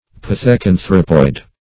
pithecanthropoid - definition of pithecanthropoid - synonyms, pronunciation, spelling from Free Dictionary
Pith`e*can"thrope, n. -- Pith`e*can"thro*poid, a.
pithecanthropoid.mp3